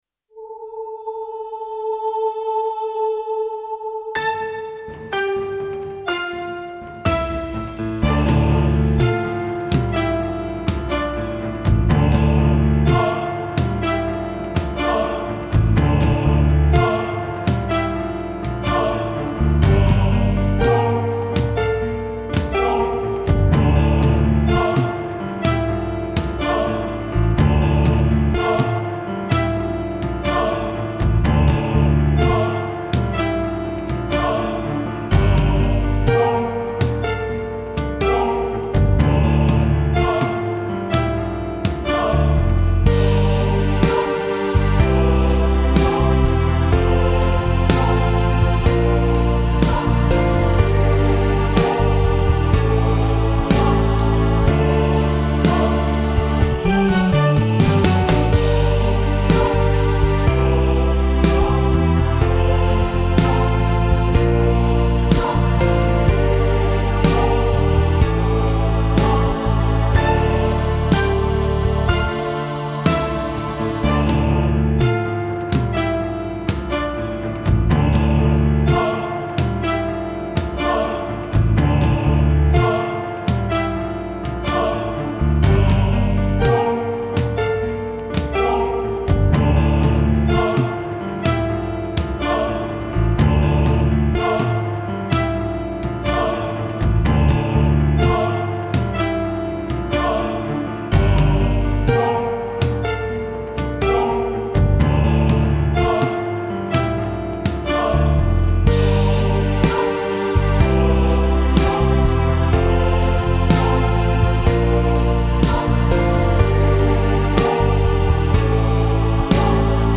Sang: «Jesus ble tatt til fange» (fader automatisk ut)